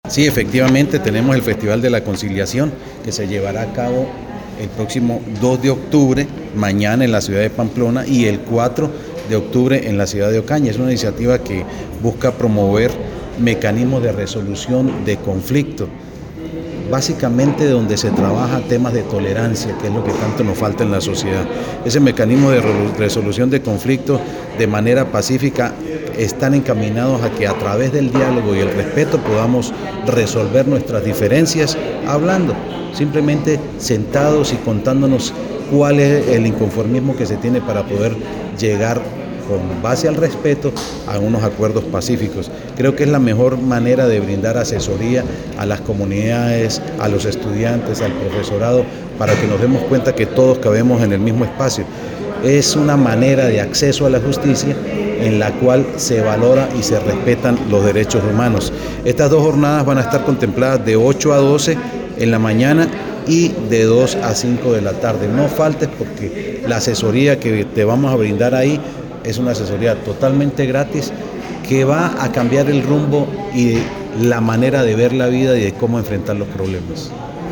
Audio de Jhonny Peñaranda, secretario de Gobierno.
Audio-de-Jhonny-Penaranda-secretario-de-Gobierno.-Festival-de-la-Conciliacion.mp3